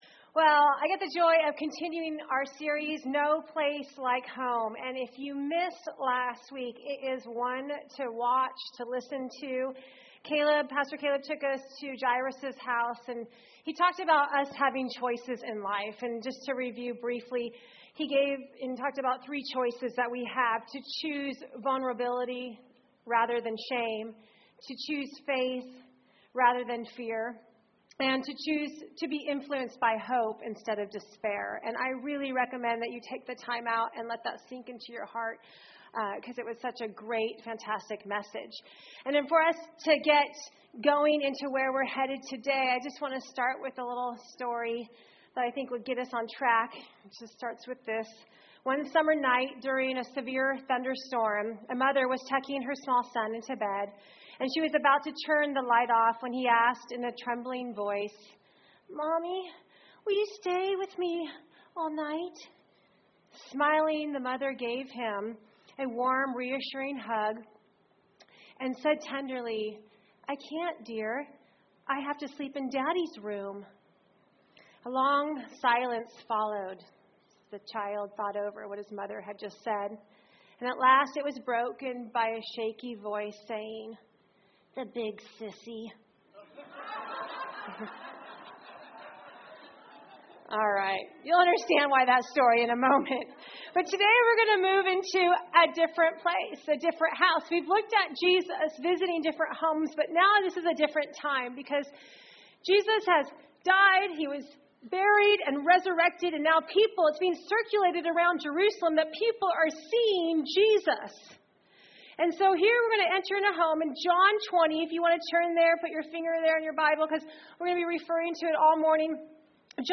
Recorded at New Life Christian Center, Sunday, November 22, 2015 at 11 AM.